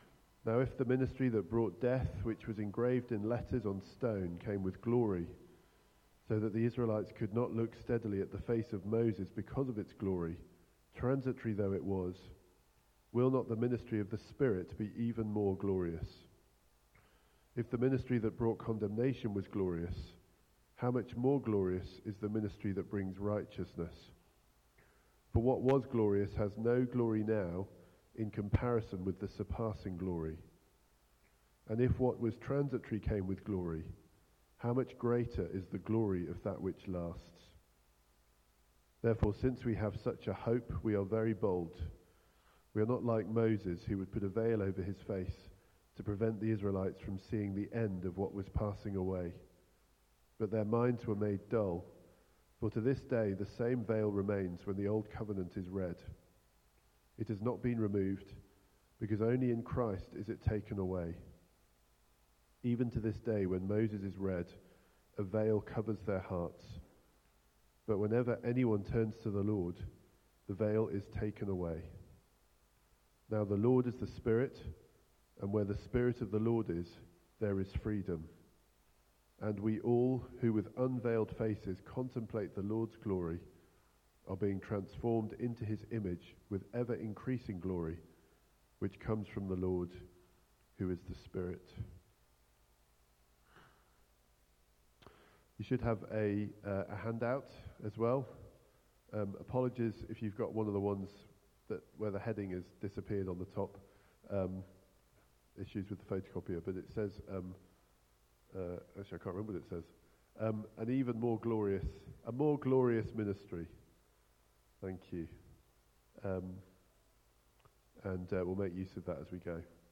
Media Library The Sunday Sermons are generally recorded each week at St Mark's Community Church.
Series: Strength in weakness Theme: A more glorious ministry Sermon